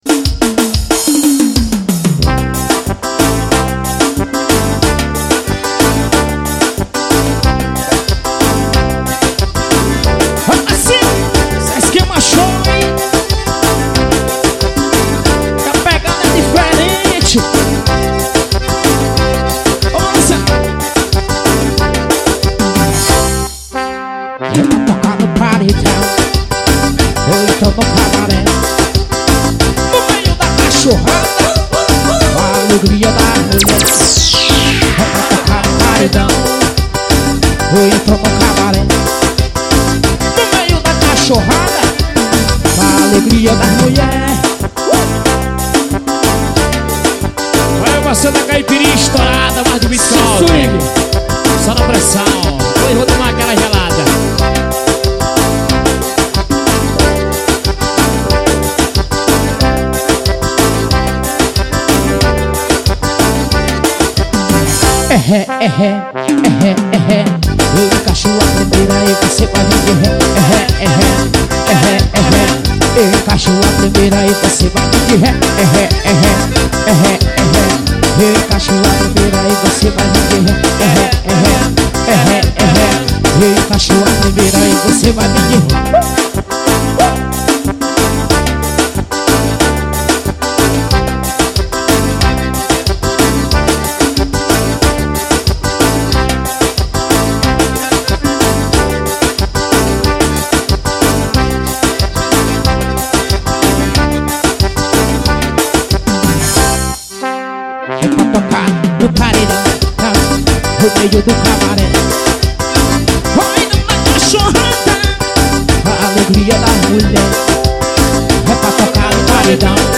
Composição: forro.